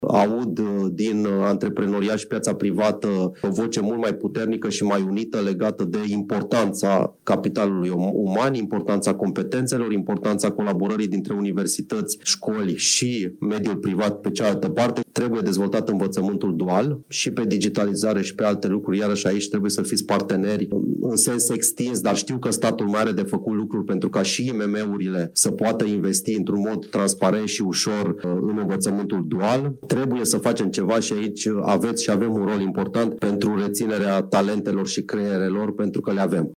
Irinu Darău, la Consiliul Național al IMM-urilor din România, unde a fost lansat Indexul Digitalizării României – un instrument de monitorizare a performanței digitale a IMM-urilor.